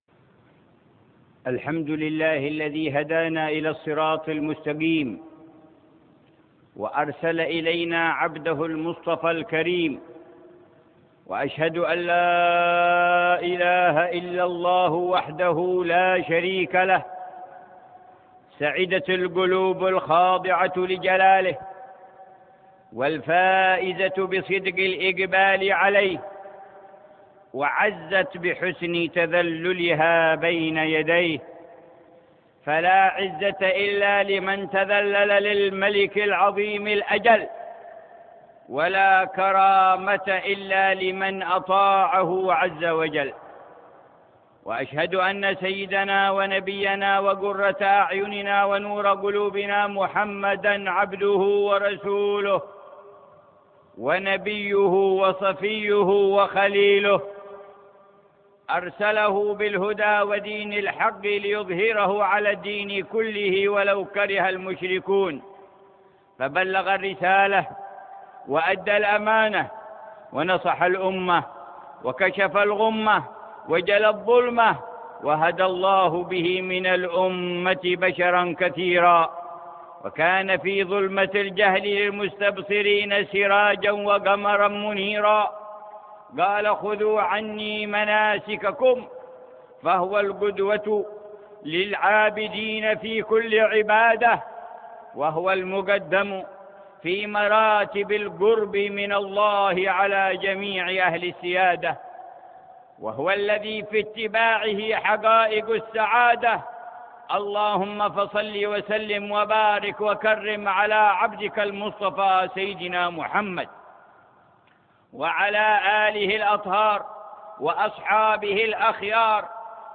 خطبة جمعة في جامع الشيخ زايد بمدينة أبوظبي تاريخ 23 ذي القعدة 1429هـ بعنوان: فضائل الحج.